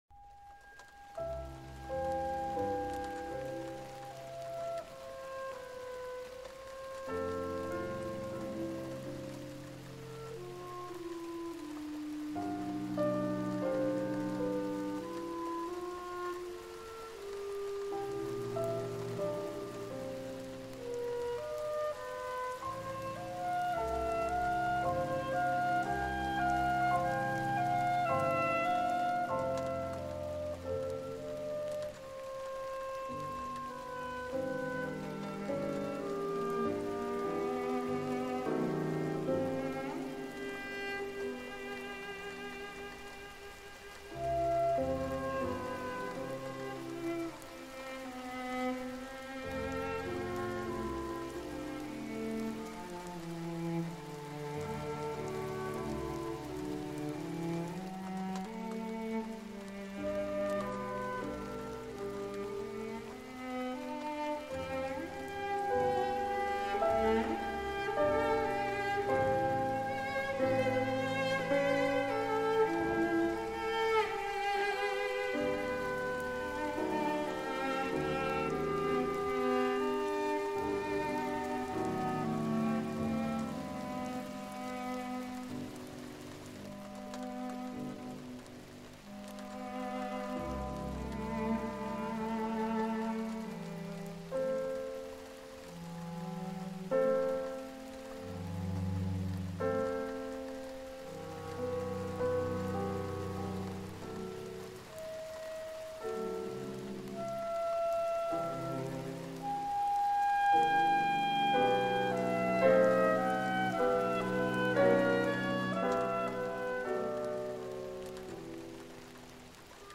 雨が静かに窓を叩くように、雨の音が思考を包み込む。
目を閉じれば広がる、木々の揺れる音、風に溶ける水のせせらぎ、夜空にひっそりと響く虫の声。
勉強BGM